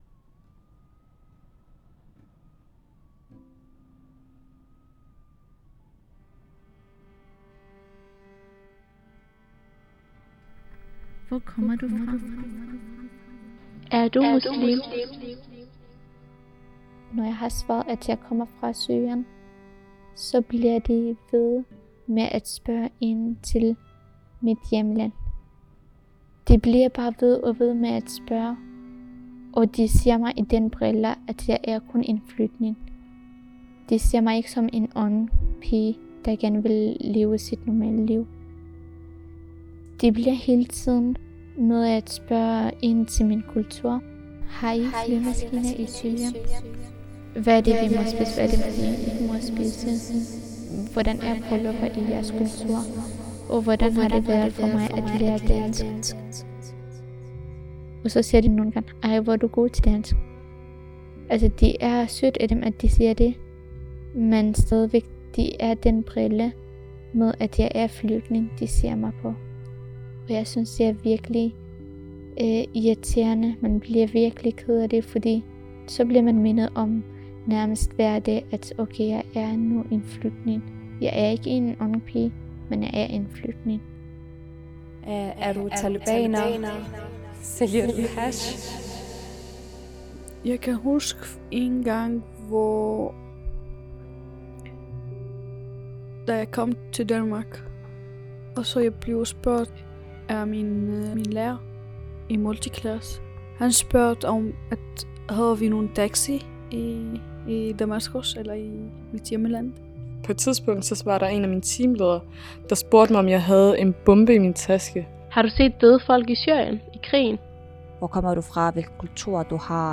Det følgende er en lydinstallation, som sætter fokus på de absurde spørgsmål, mange unge med flugterfaringer oplever at blive mødt af i Danmark.